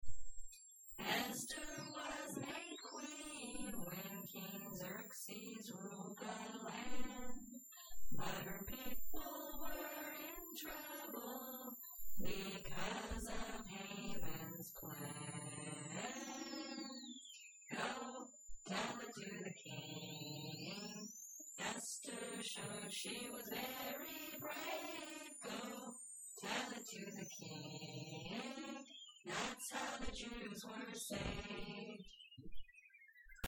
To the tune "Go Tell it on the Mountain".